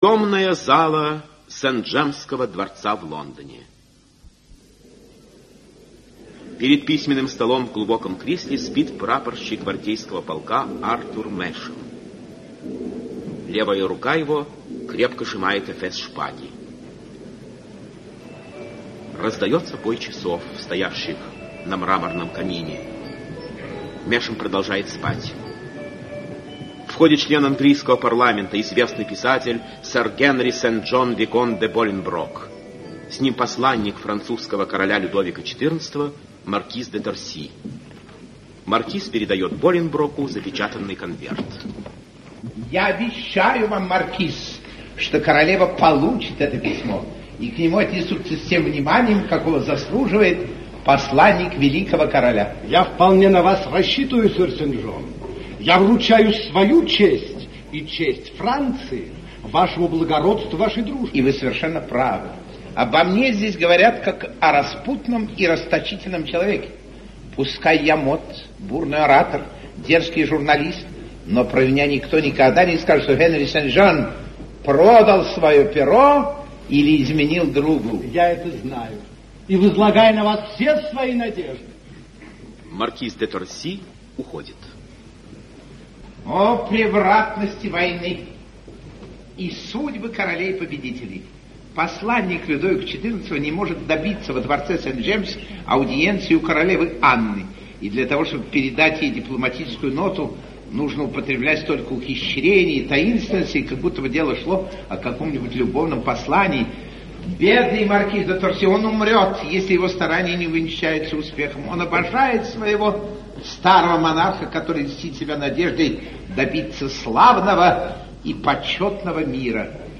Aудиокнига Стакан воды (спектакль) Автор Эжен Скриб Читает аудиокнигу Актерский коллектив.